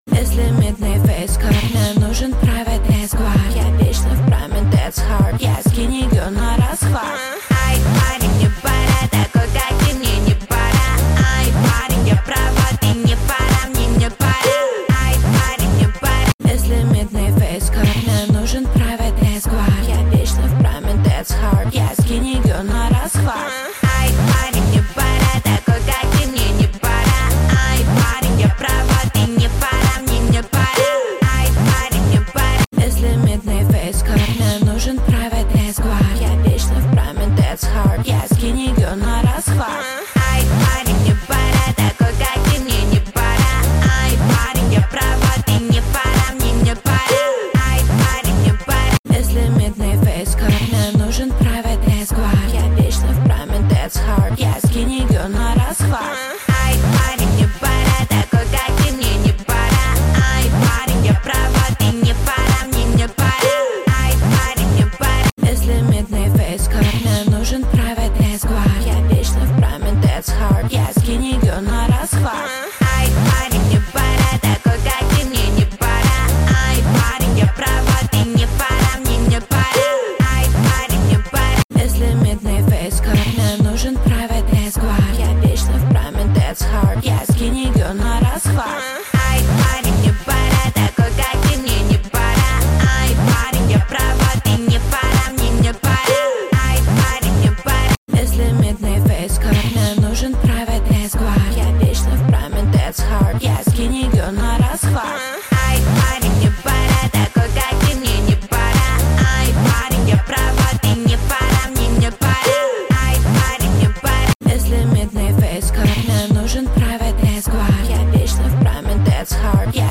Качество: 320 kbps, stereo
Поп музыка, Новинки